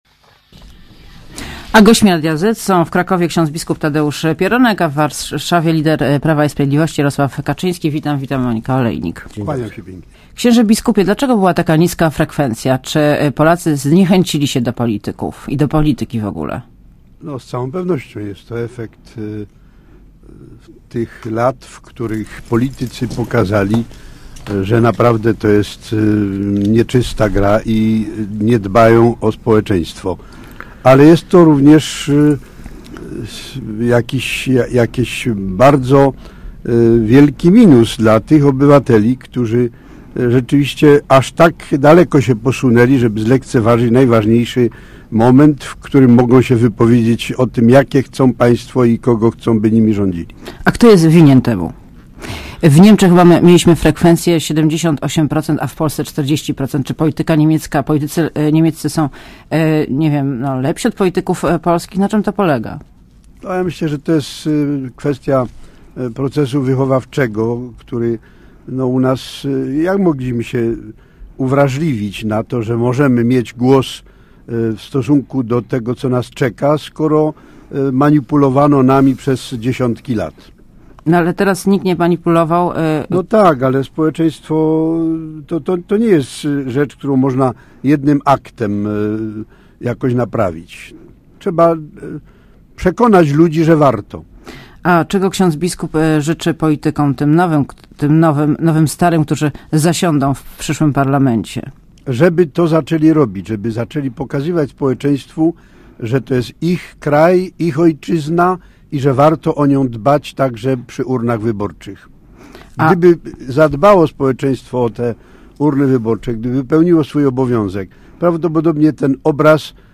Gośćmi Radia ZET są: w Krakowie – bp Tadeusz Pieronek, a w Warszawie lider Prawa i Sprawiedliwości, Jarosław Kaczyński.